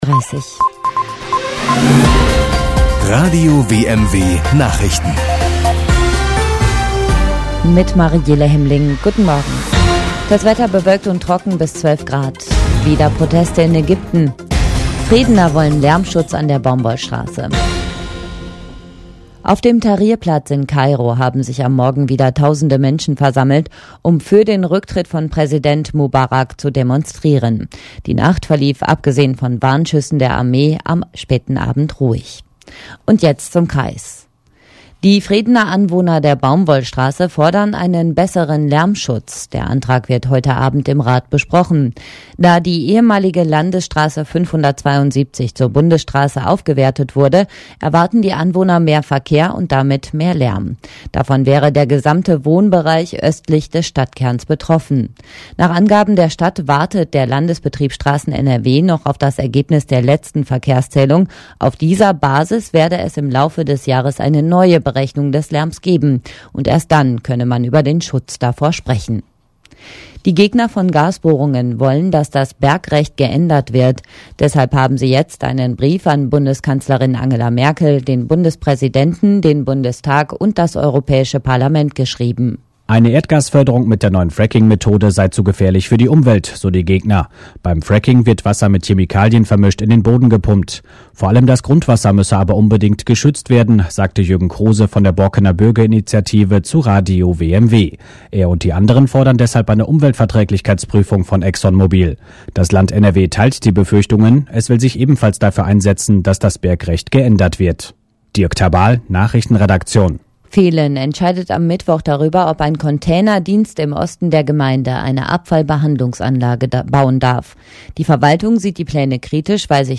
In einem Radiobeitrag berichtete Radio WMW bereits von dem Schreiben.